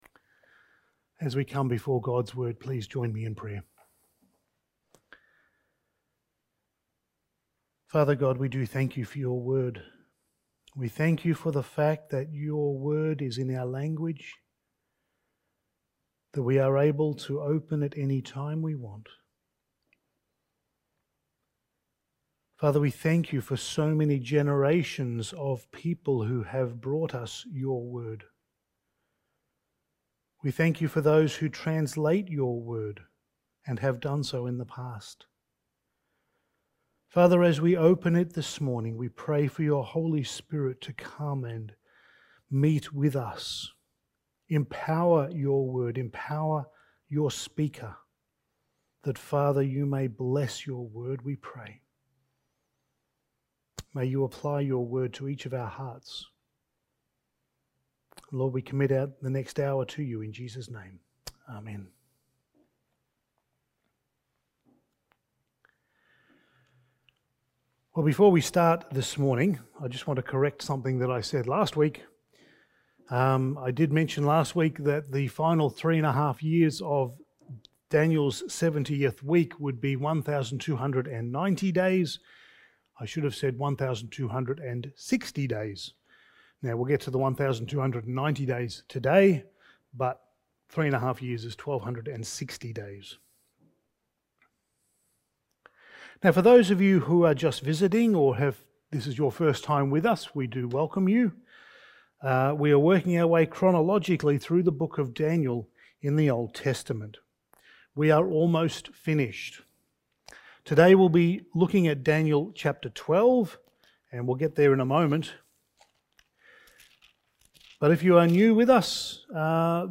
Passage: Daniel 12:1-13 Service Type: Sunday Morning